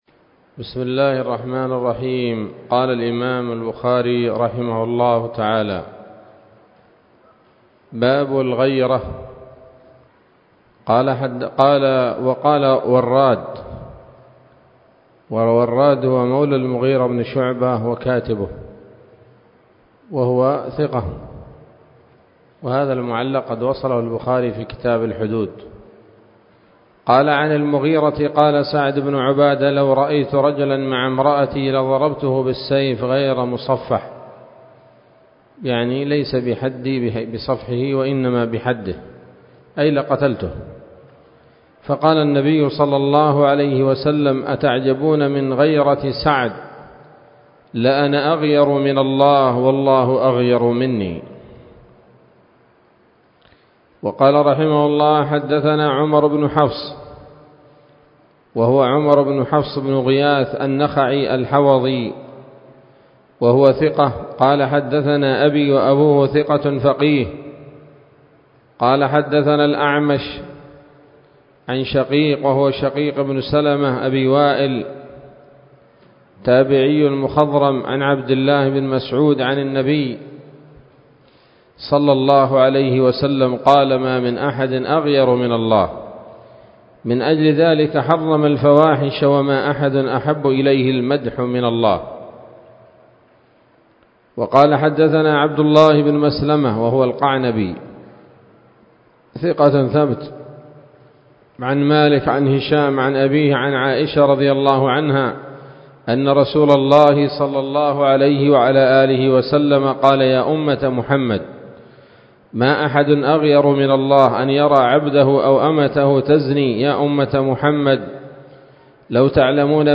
الدرس الرابع والثمانون من كتاب النكاح من صحيح الإمام البخاري